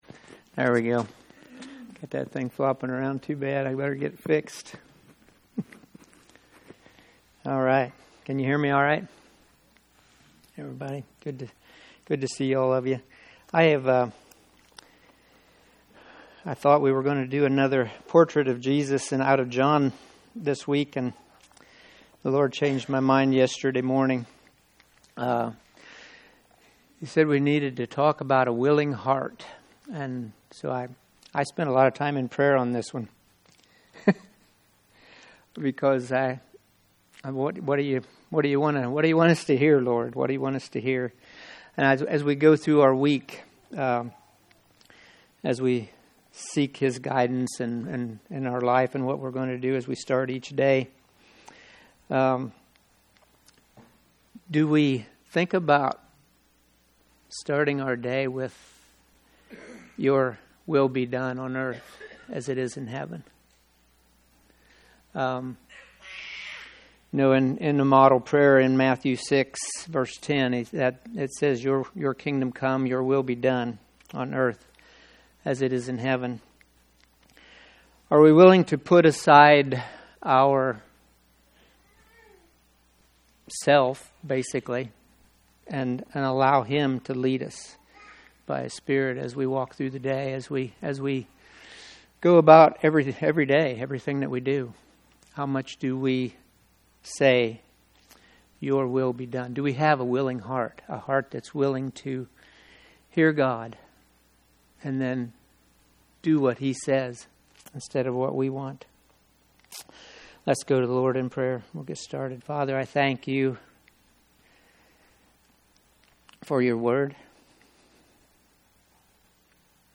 2025 A Willing Heart Preacher